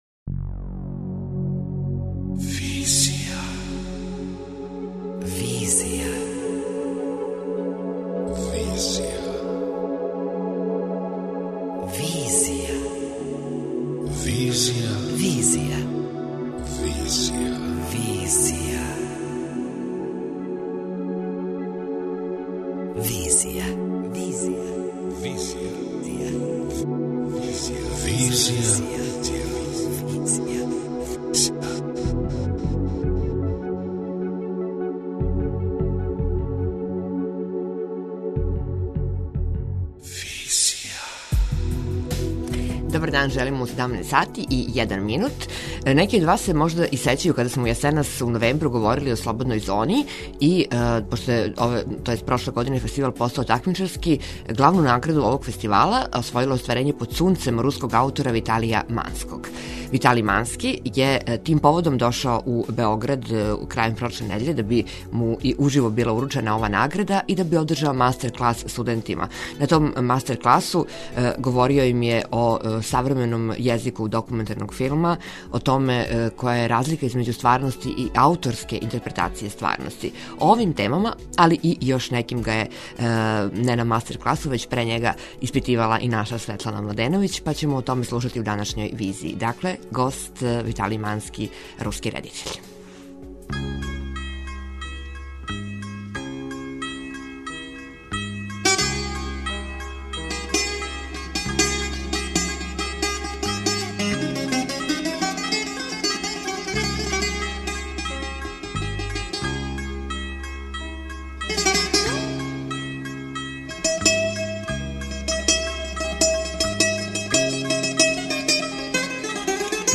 Интервју: Виталиј Мански, редитељ